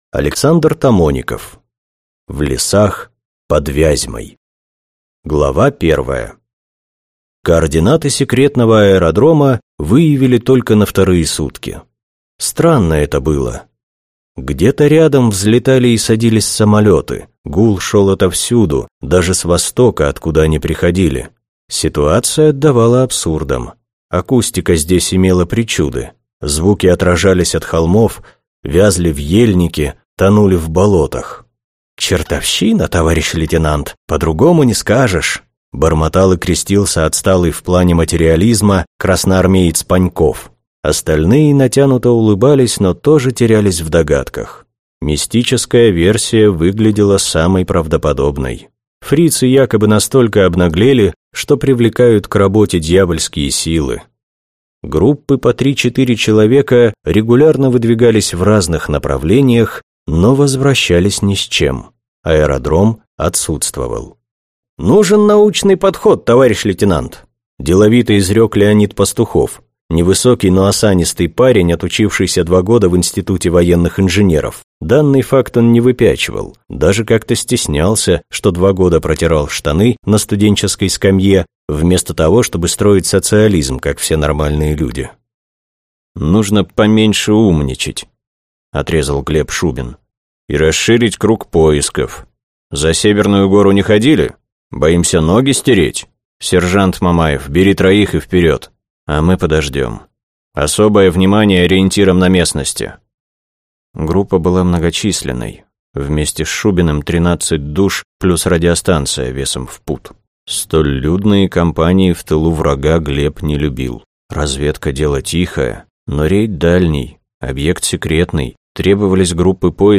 Аудиокнига В лесах под Вязьмой | Библиотека аудиокниг